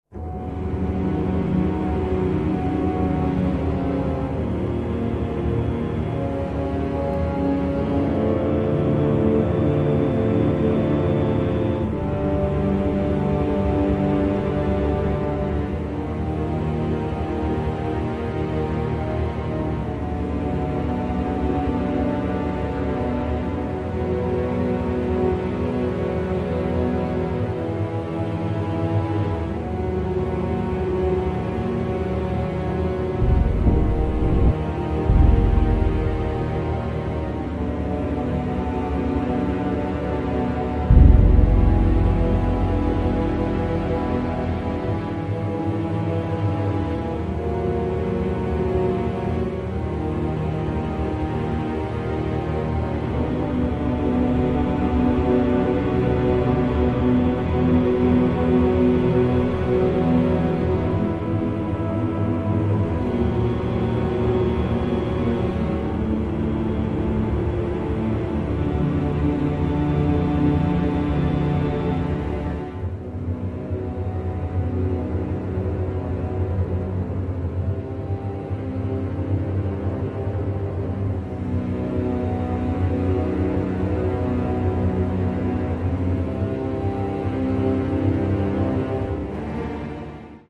2 ambient tracks and 11 orchestral styled tracks.